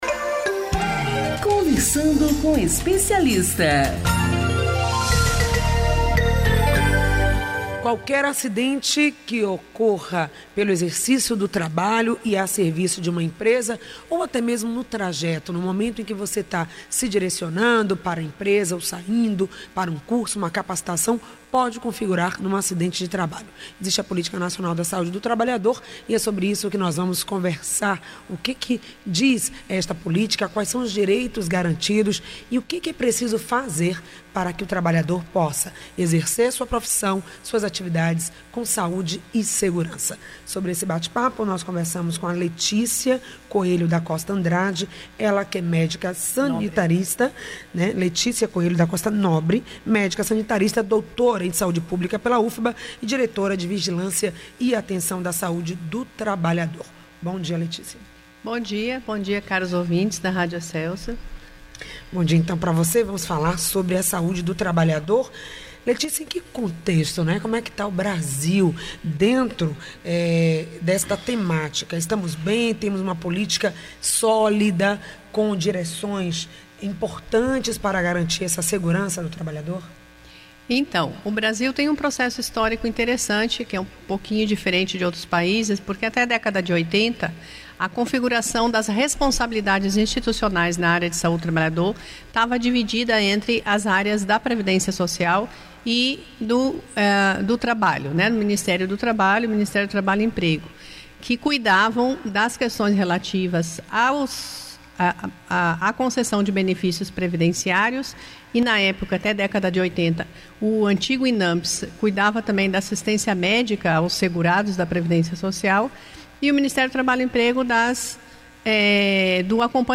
O programa exibido pela Rádio AM 840(em 28.04.16 das 8 às 9h) .